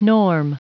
Prononciation du mot norm en anglais (fichier audio)
Prononciation du mot : norm